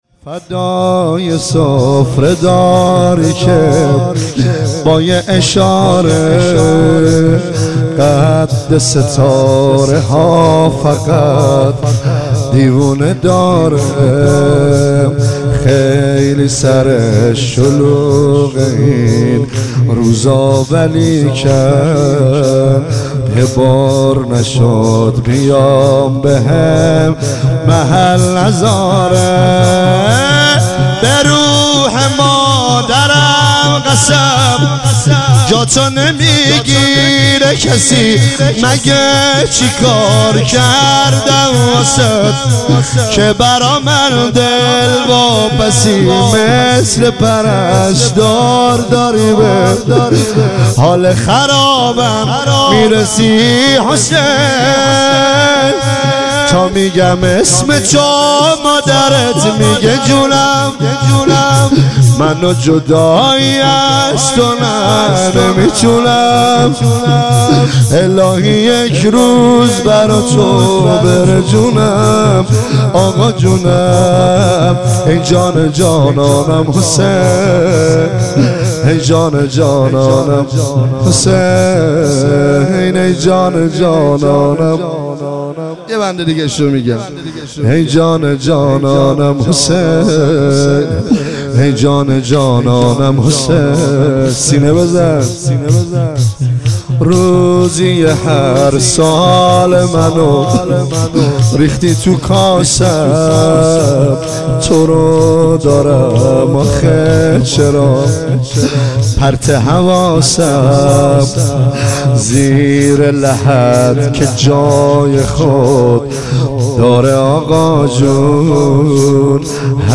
متن دانلود مداح سبک مدح نوع فایل حجم (MB) پخش فایل
شور